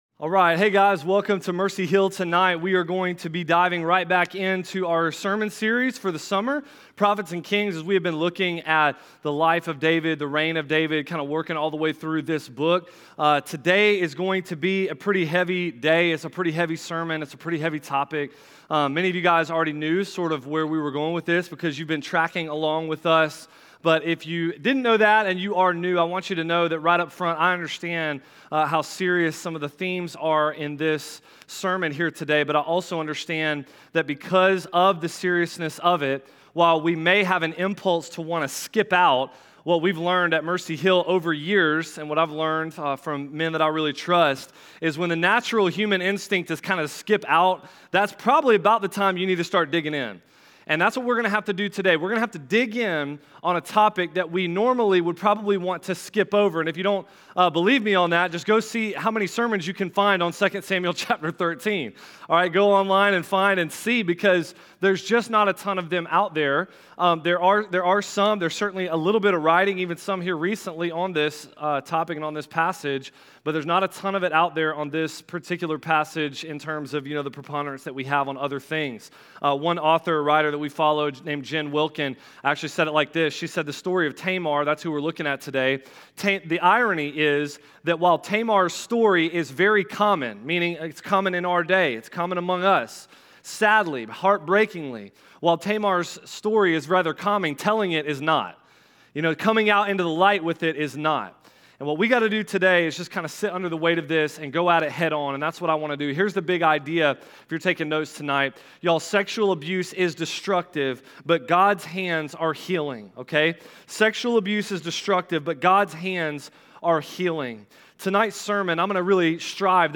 Mercy Hill Church